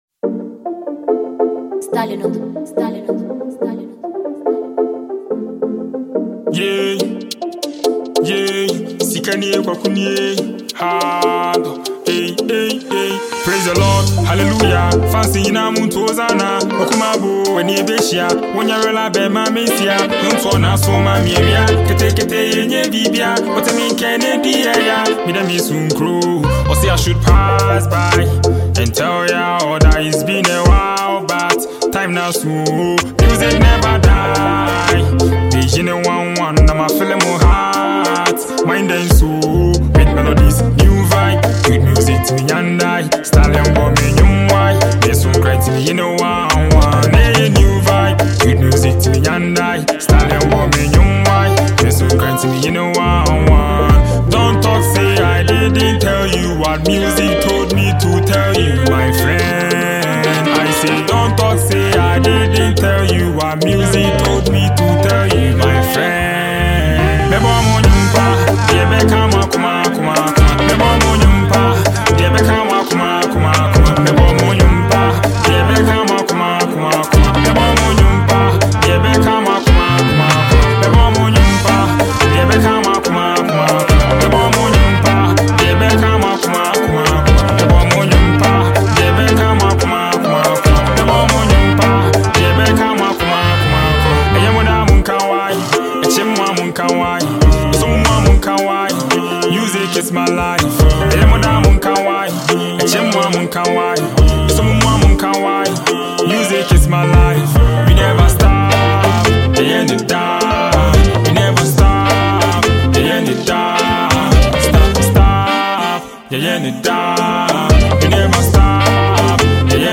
Please this is an afropop mixed with mordern afrobeat genre.